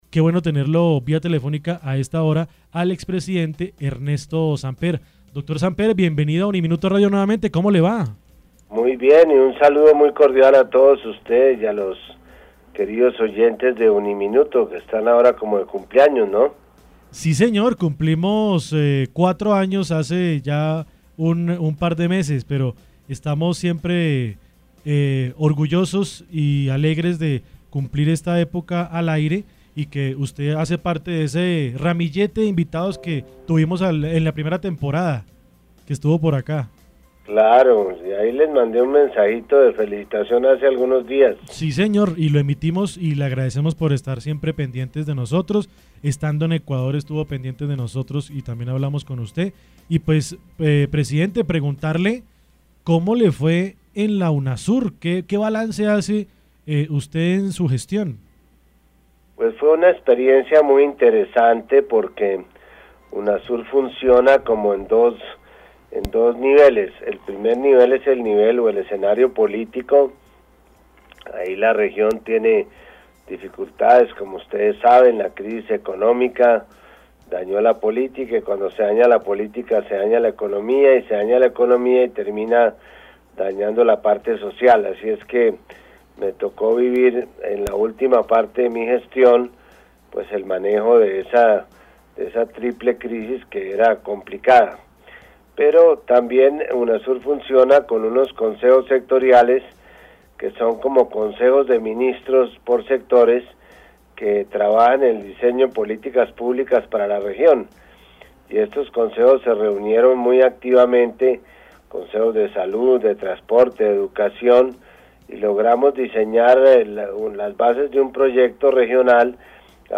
En diálogo con UNIMINUTO Radio estuvo el expresidente de Colombia y ex secretario de la Unasur Ernesto Samper, quien mostró su desacuerdo y rechazo sobre lo que está pasando en Venezuela.